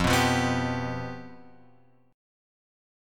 Fsus2b5 chord {1 2 3 0 0 3} chord